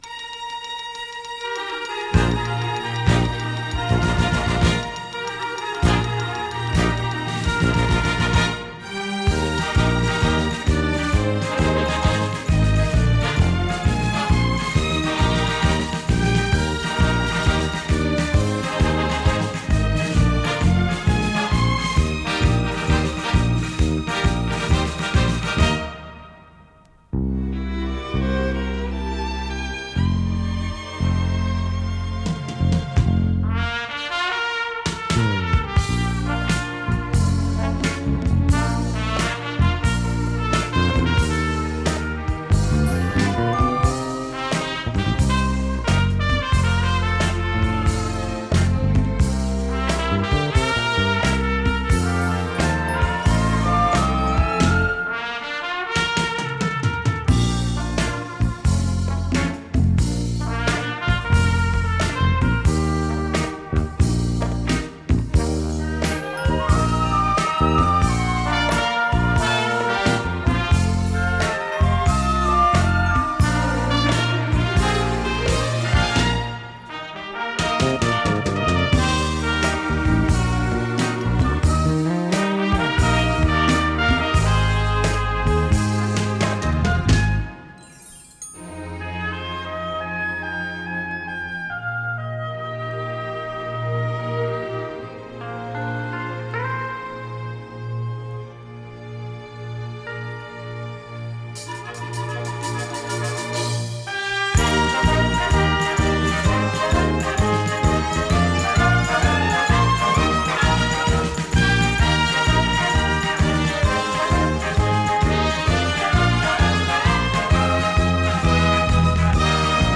fox lento.
Opereta cómica en dos actos